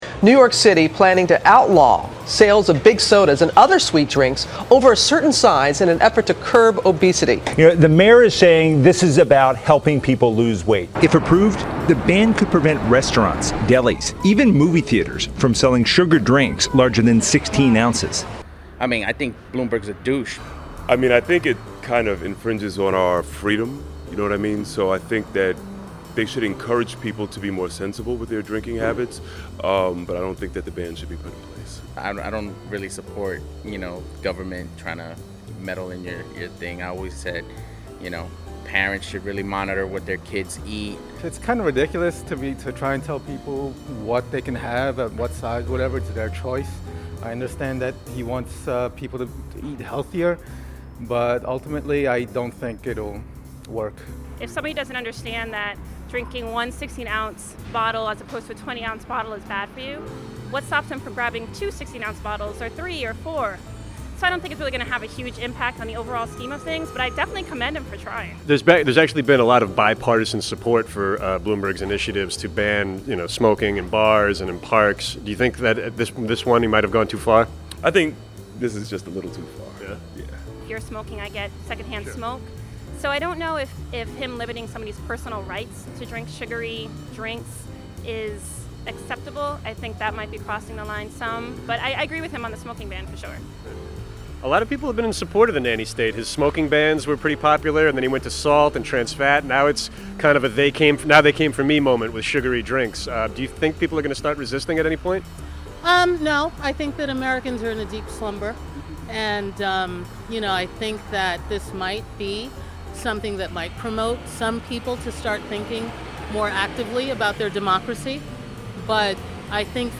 took to the streets to ask New Yorkers what they thought about Bloomberg and the ban.